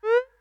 window-unminimized.ogg